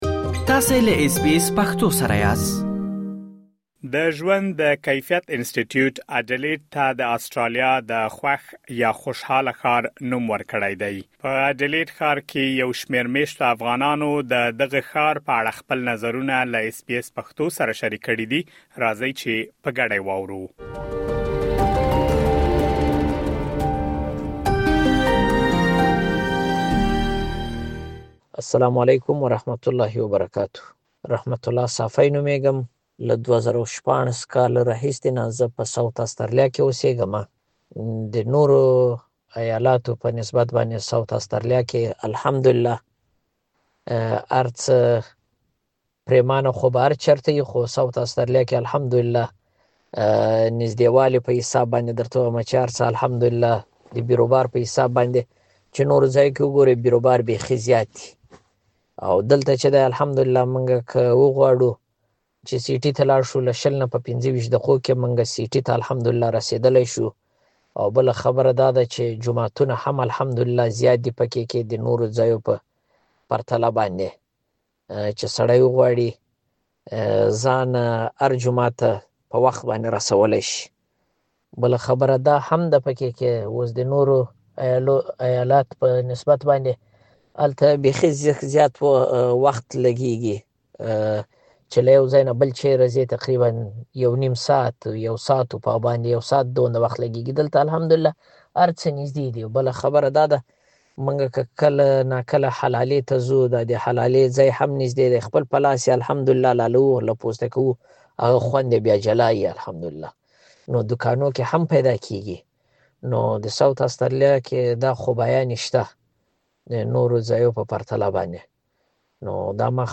په اډېلېډ ښار کې یو شمېر مېشتو افغانانو له اس بي اس پښتو سره د همدغې موضوع په اړه خپل نظرونه شریک کړي، تاسو کولی شئ دلته یې واورئ.